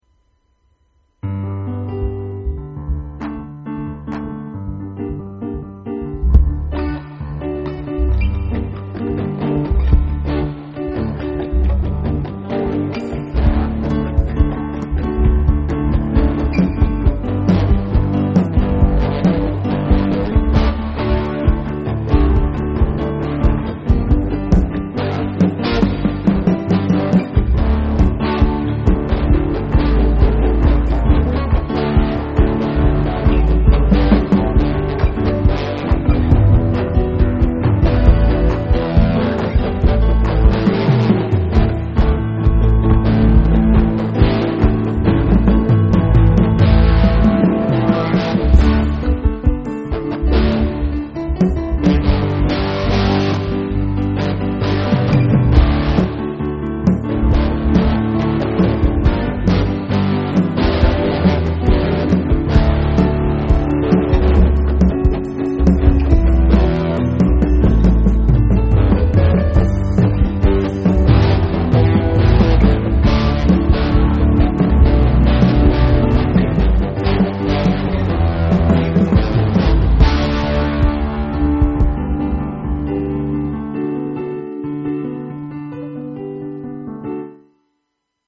with electric guitar